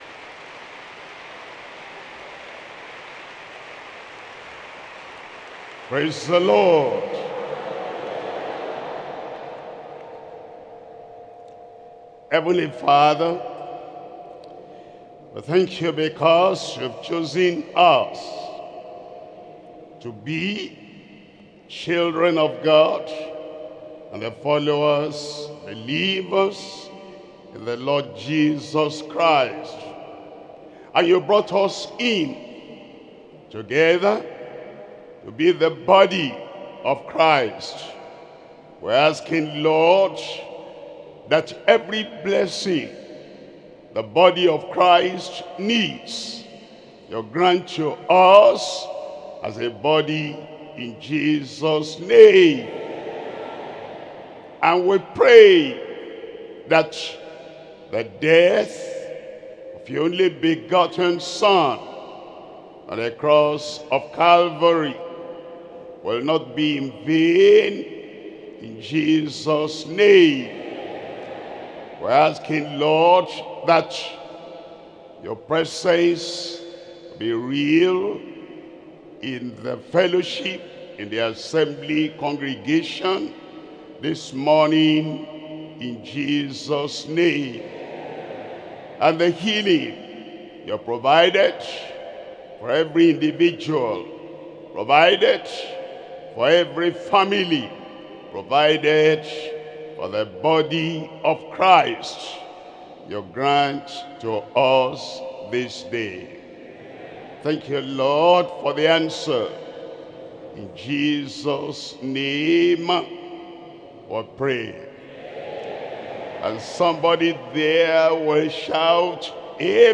Sermons - Deeper Christian Life Ministry
2026 Global Easter Retreat